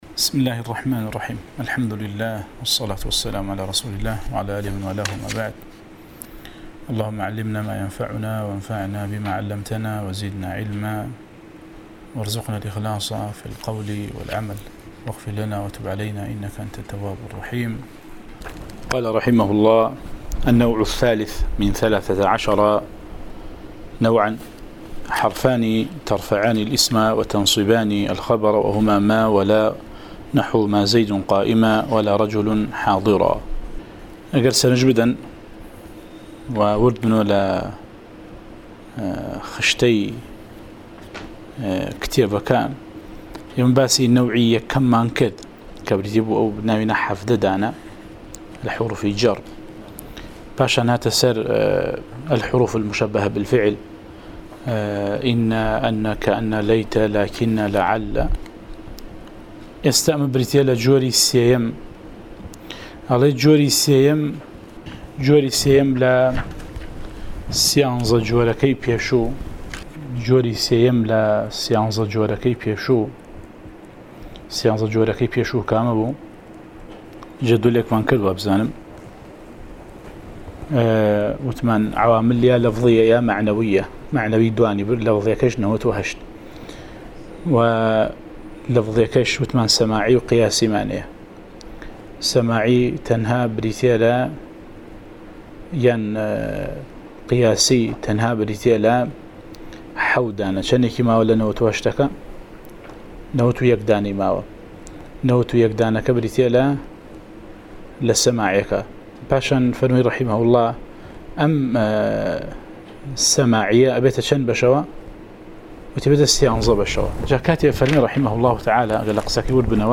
07 ـ شەرحی العوامل المائة، (عوامل الجرجانی) (نوێ) وانەی دەنگی: